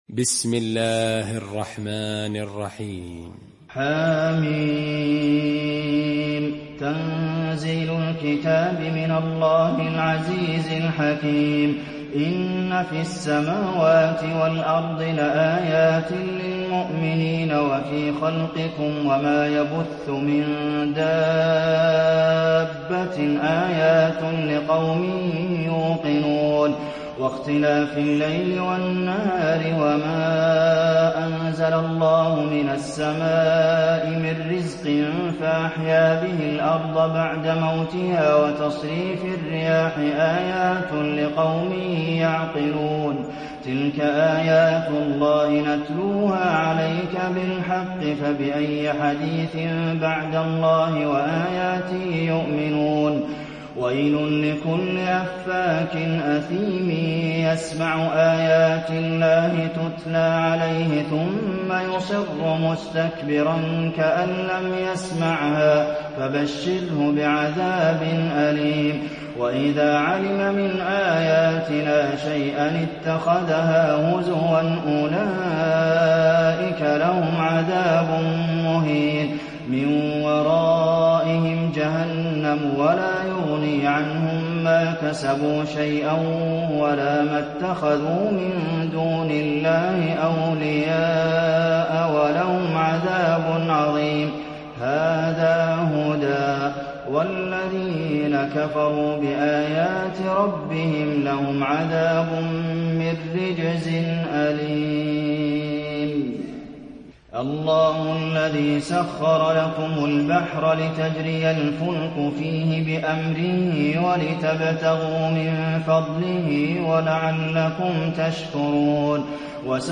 المكان: المسجد النبوي الجاثية